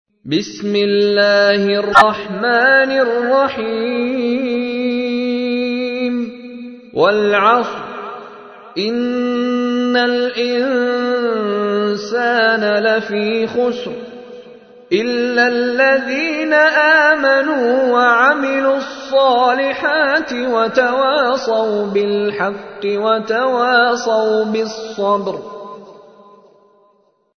تحميل : 103. سورة العصر / القارئ مشاري راشد العفاسي / القرآن الكريم / موقع يا حسين